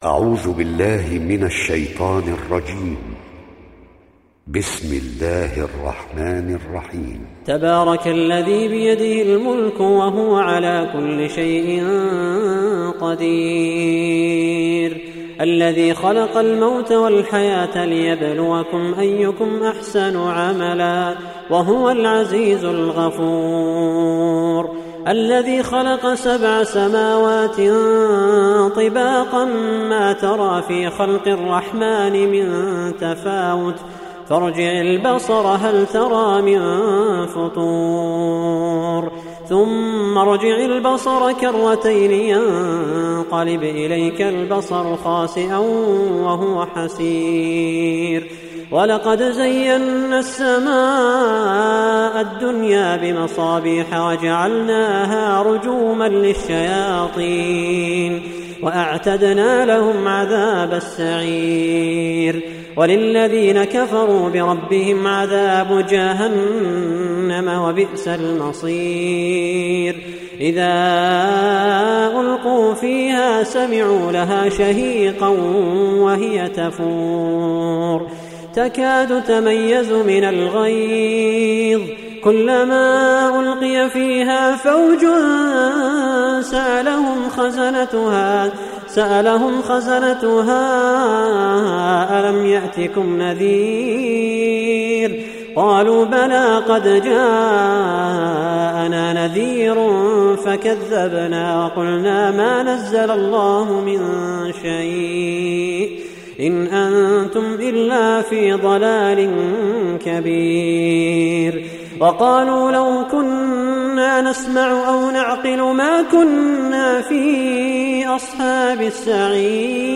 Riwayat Hafs dari Asim